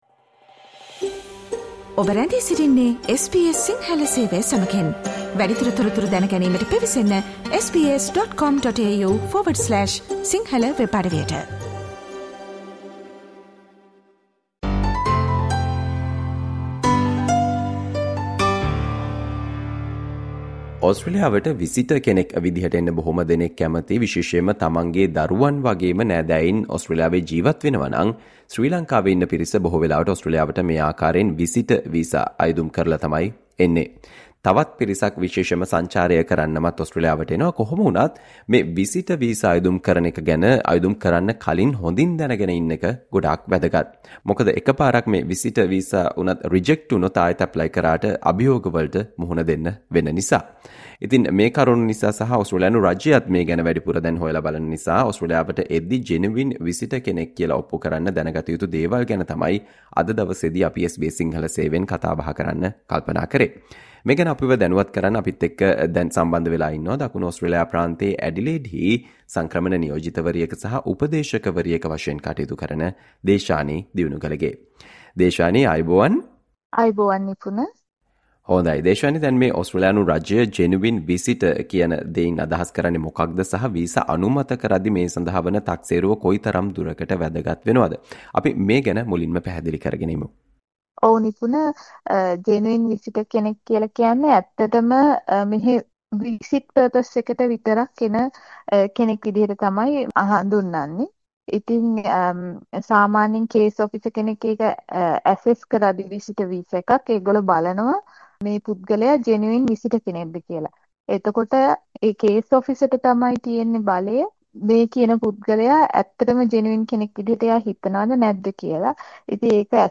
ඔබ ඕස්ට්‍රේලියාවට පැමිණෙද්දී 'Genuine Visitor ' කෙනෙක් බව ඔප්පු කිරීමට දන සිටිය යුතු දේ පිළිබඳව SBS සිංහල සේවය සිදු කල සාකච්චාවට සවන්දෙන්න